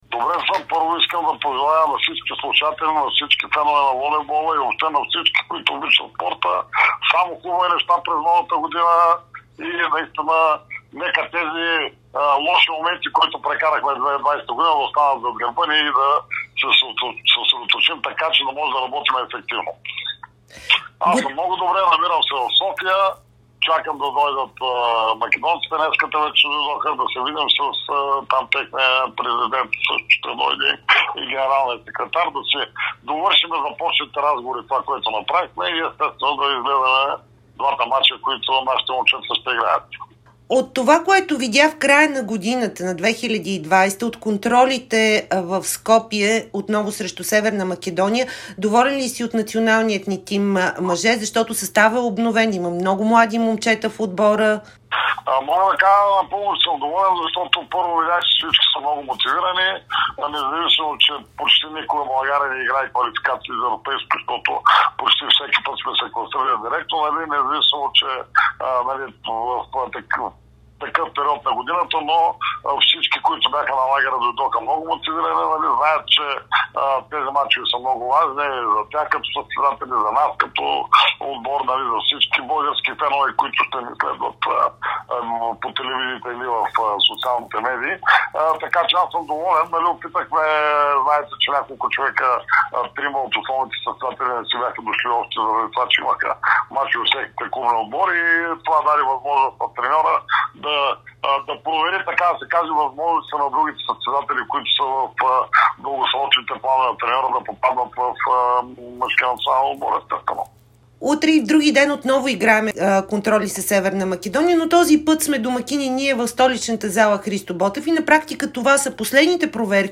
Президентът на БФВ Любо Ганев разкри пред Дарик радио и dsport, че федерацията прави всичко възможно да предава българските мачове от европейската квалификация в Израел в Youtube. Липсата на публика и строгите ограничения заради пандемията според него са причина за липсата на телевизионно излъчване.